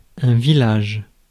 Ääntäminen
IPA: [vi.laʒ]